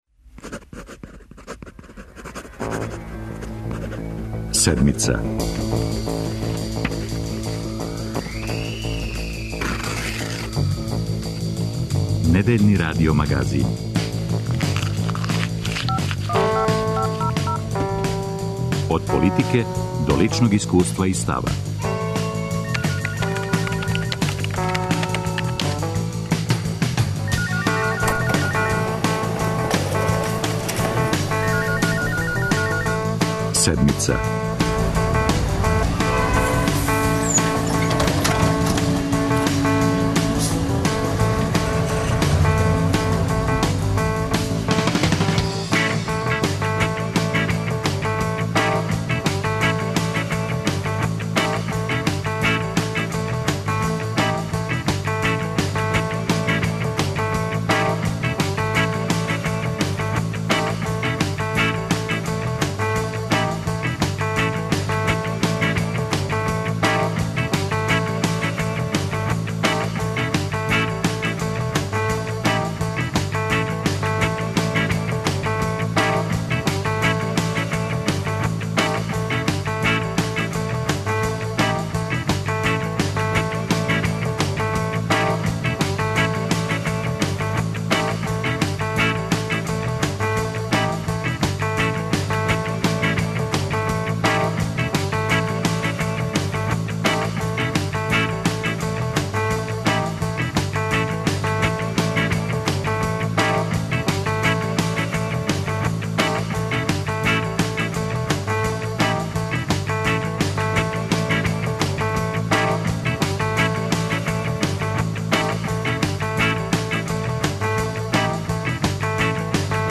У емисији разговарамо о томе какве поруке у Србију доноси руски председник владе Владимир Путин. Уочи посете, разговарали смо са амбасадором Александром Конузином који потврђује да ће бити речи и о економским темама, пре свега о енергетици и инвестицијама.